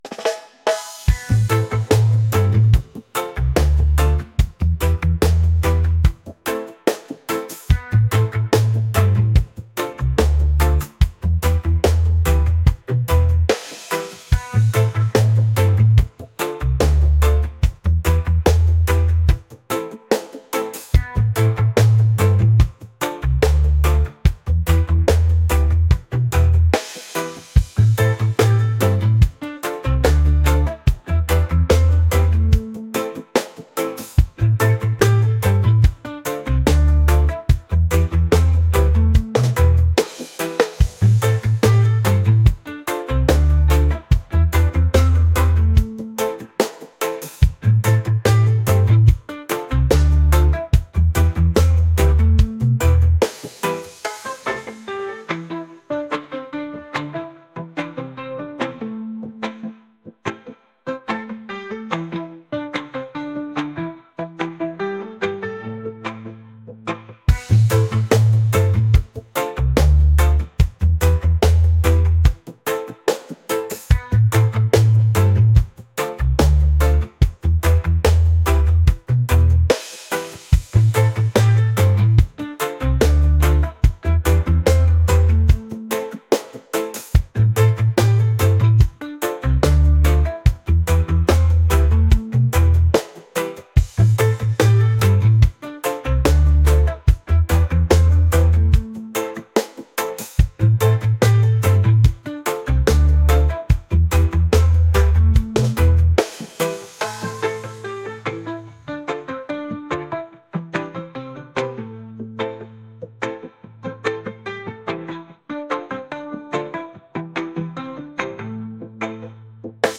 reggae | lounge